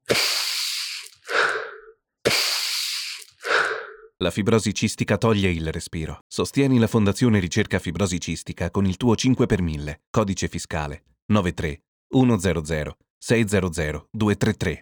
Spot radio 5×1000 – 15″